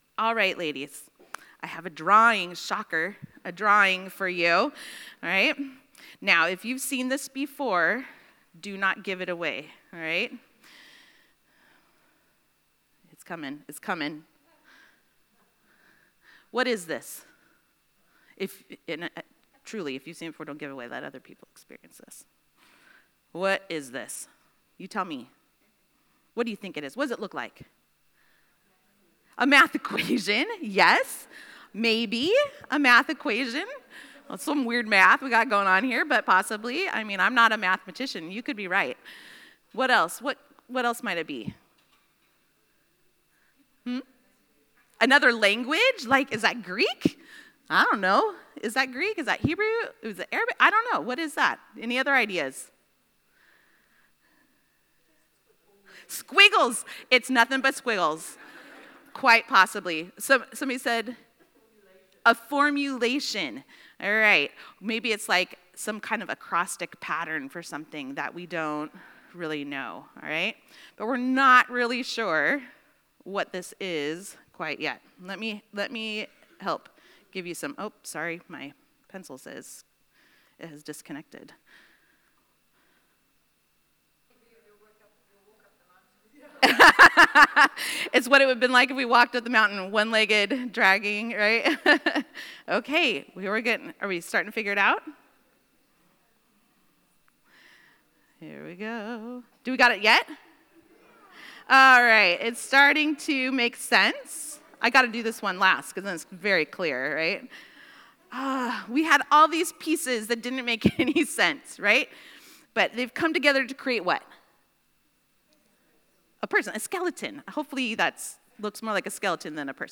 Cape Town 2025 (Women) We are often tempted to say more or less than the Scriptures say.